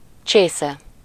Ääntäminen
Synonyymit tonsure taille crawl section coupure jatte Ääntäminen France: IPA: [kup] Haettu sana löytyi näillä lähdekielillä: ranska Käännös Ääninäyte 1. csésze 2. hajvágás 3. findzsa Suku: f .